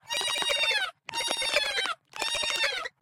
Song: Baby Cry5b